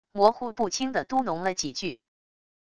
模糊不清的嘟哝了几句wav音频